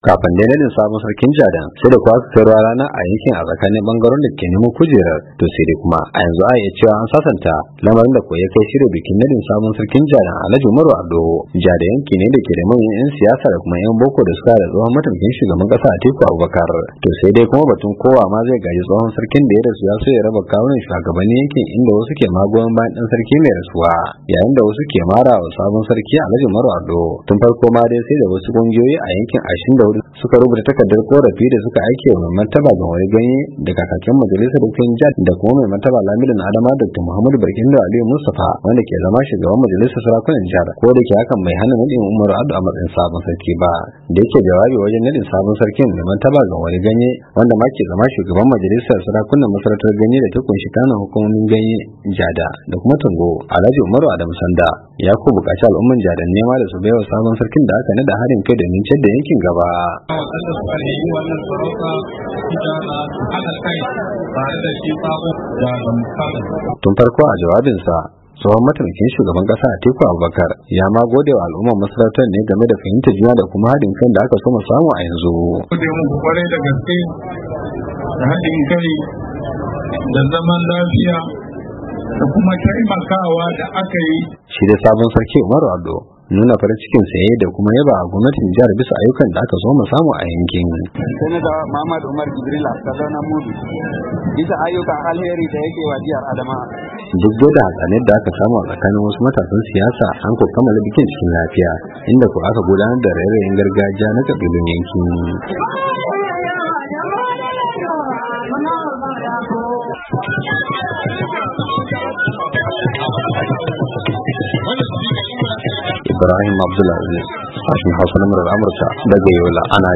Duk dai da hatsaniyar da aka samu a tsakanin wasu matasan siyasa an kamala bikin nadin sabon sarkin lafiya, inda aka gudanar da raye-rayen gargajiya na kabilun yankin.